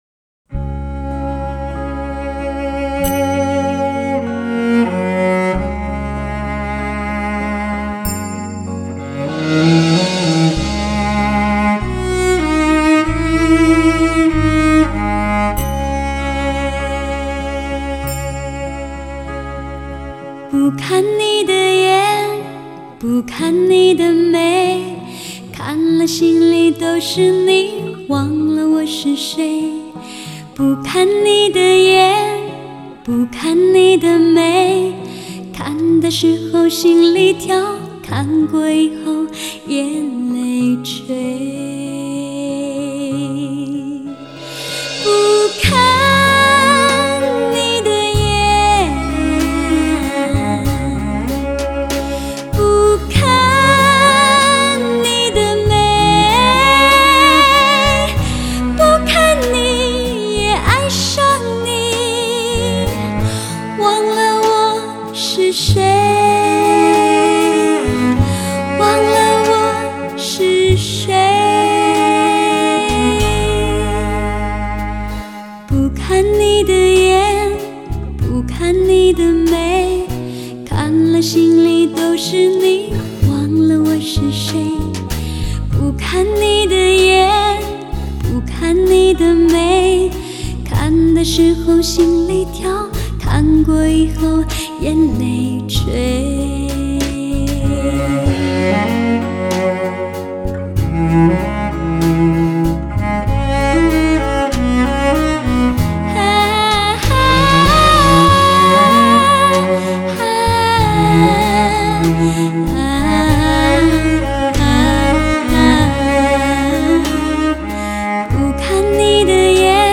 Жанр: Cinese Pop